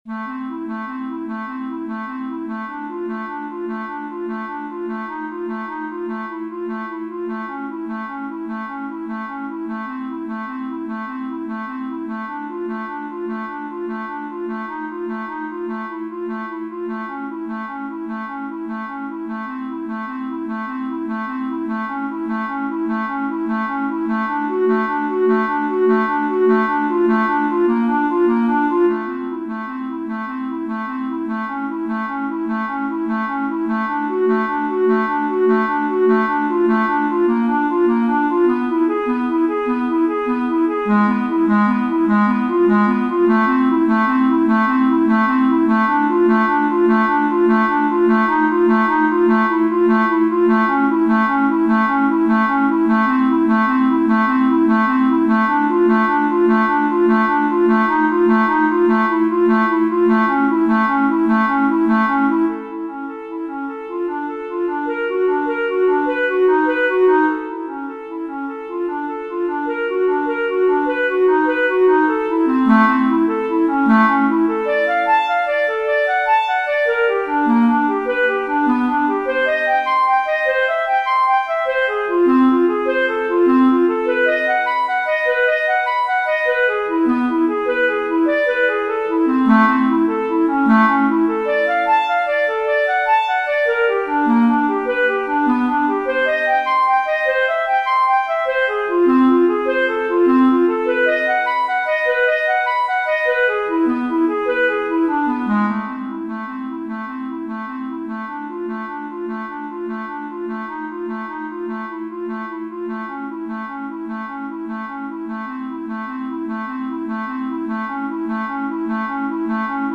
At the moment minimal music is one of my favourite genres and I tried to compose a minimalistic piece for Clarinet in A or Bb.